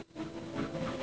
violin
Added violin